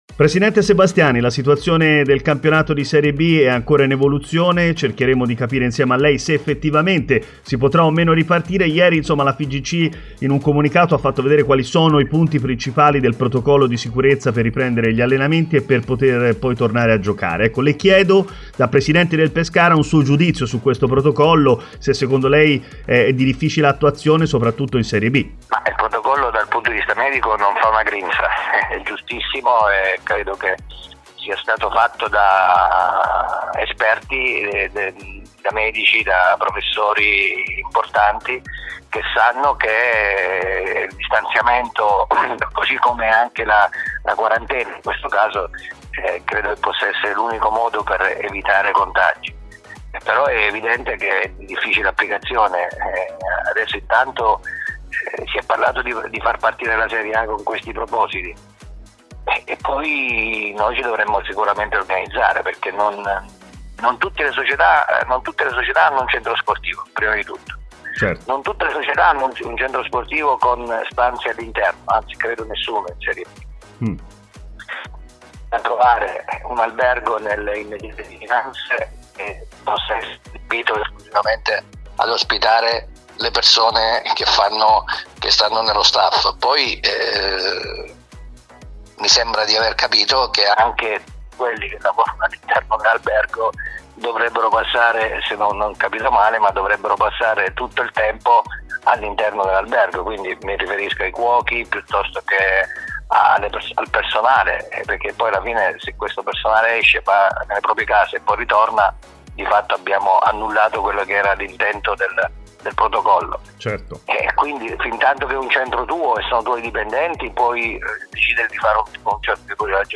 a Stadio Aperto, trasmissione in onda su TMW Radio